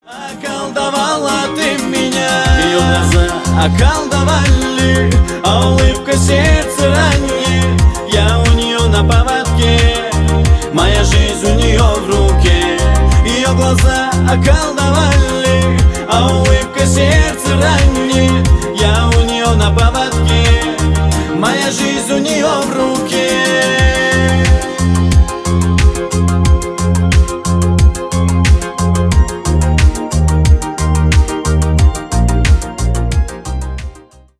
Поп Музыка
кавказские